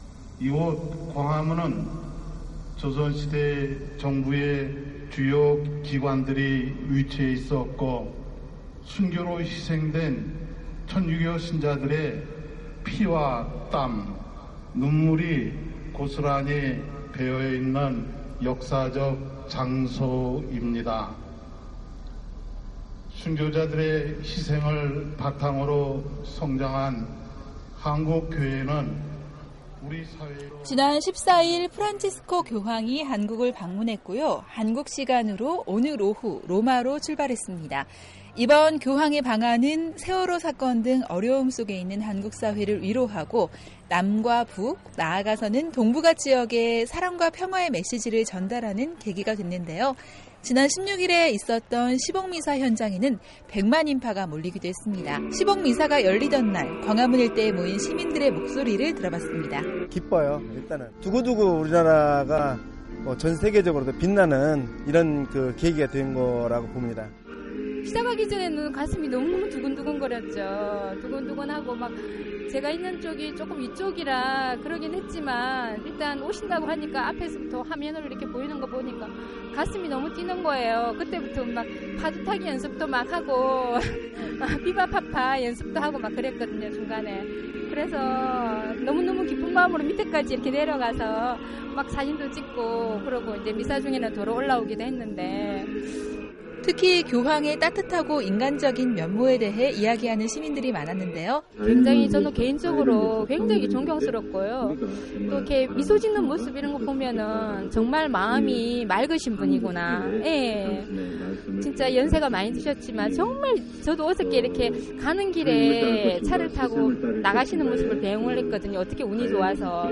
한국사회의 이모저모를 전해드리는 ‘안녕하세요 서울입니다’ 순서, 지난 14일 프란치스코 교황이 한국을 방문했고 한국시간으로 18일 오후 출국했습니다. 16일, 광화문에서는 교황이 집전하는 시복 미사가 있었는데요 그 현장에서 시민들의 목소리를 담아왔습니다